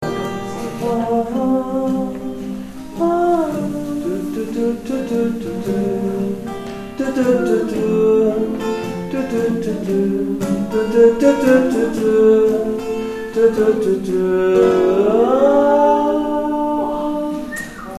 Travail de choeur
Pont n°2 Altis
pont2-altis.mp3